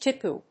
ティープー； ティプ； ティプー